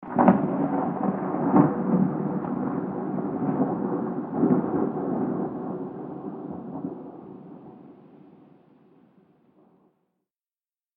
جلوه های صوتی
دانلود صدای طوفان 3 از ساعد نیوز با لینک مستقیم و کیفیت بالا